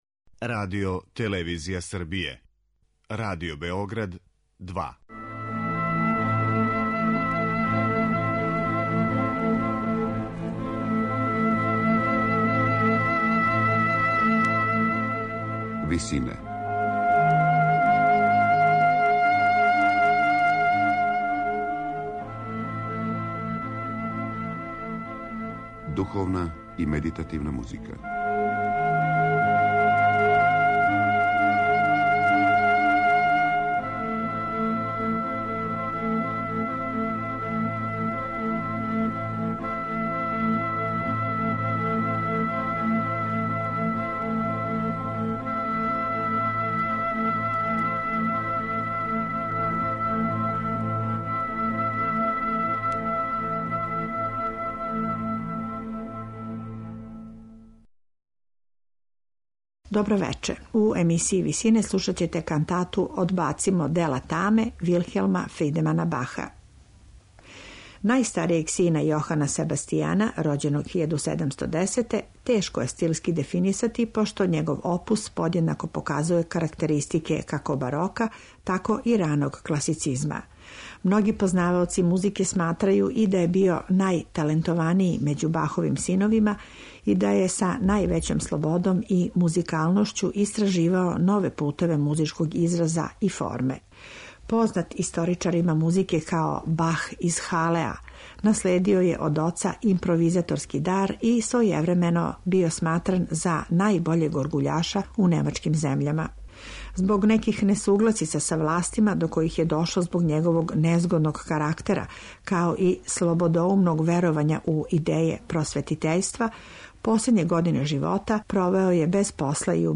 Слушаћете кантату 'Одбацимо дела таме' Вилхелма Фридемана Баха.